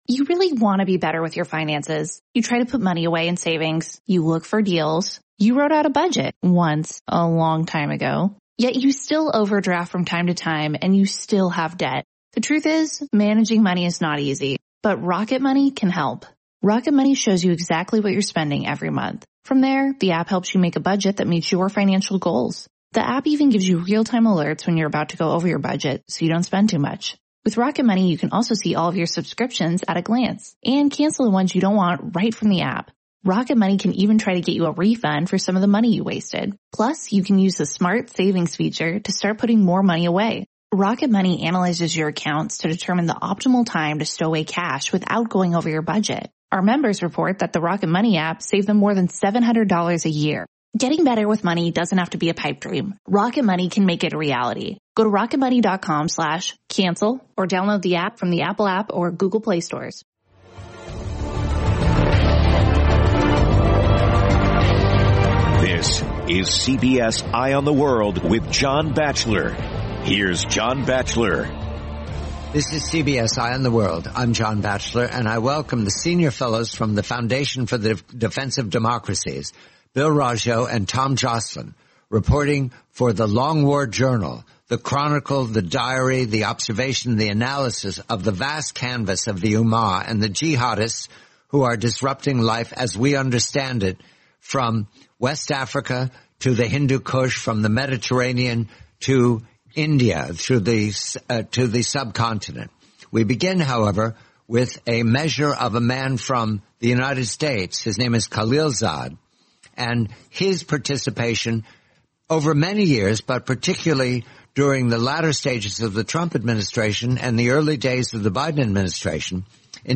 the complete, forty-minute interview